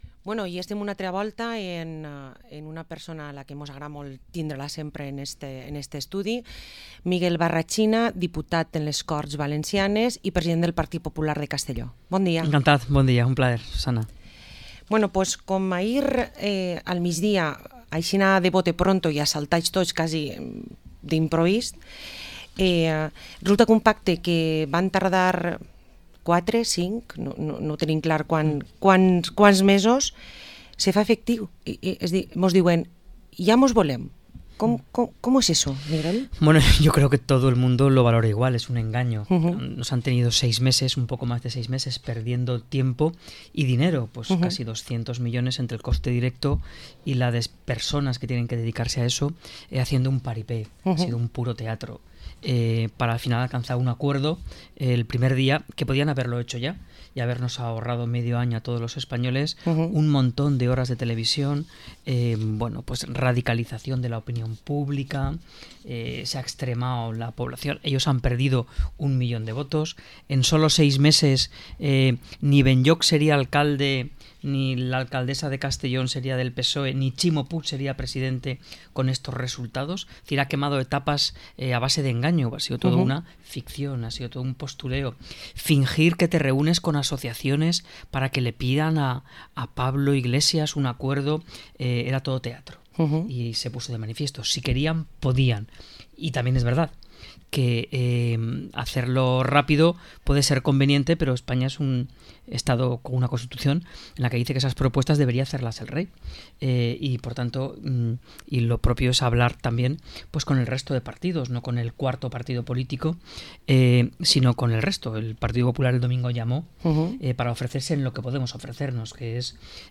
Entrevista con el líder del PP de Castellón y diputado autonómico, Miguel Barrachina. 13 de noviembre del 2019